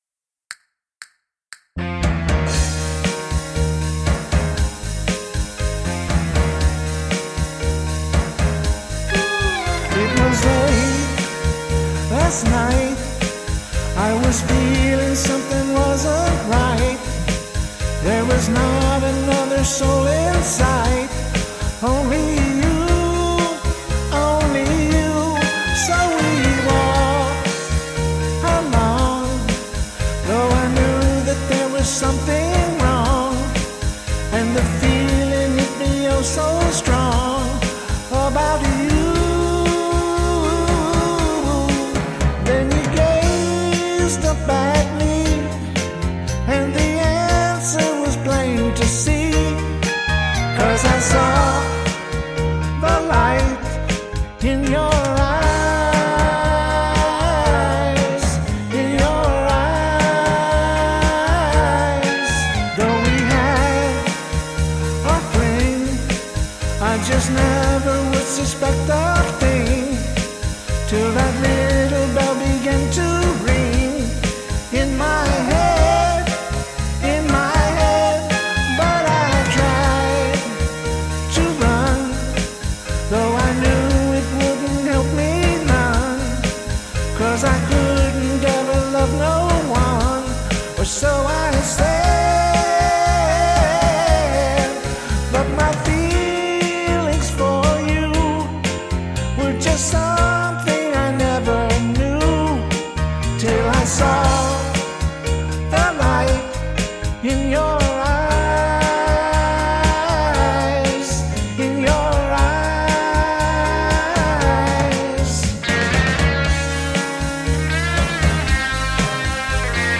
- Classic Rock Covers -